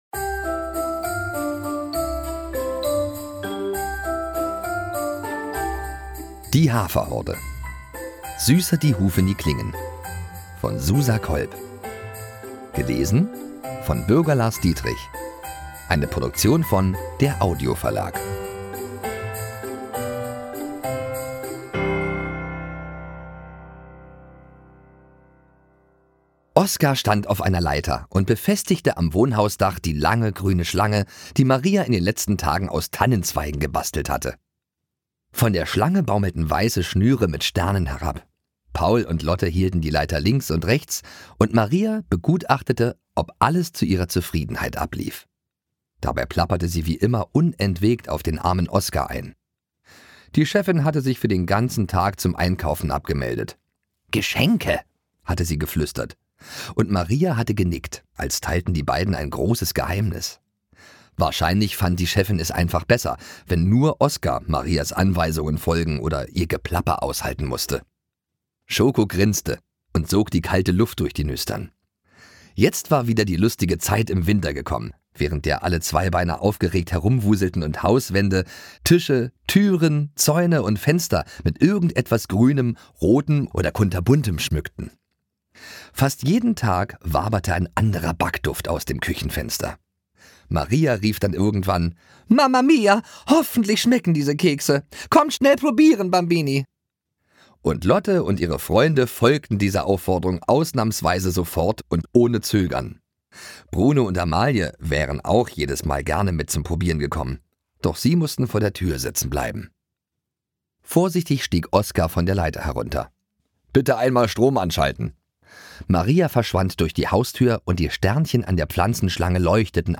Hörbuch
Die Haferhorde – Die große Box 3 (Teil 7-9) Ungekürzte Lesungen mit Bürger Lars Dietrich
Bürger Lars Dietrich (Sprecher)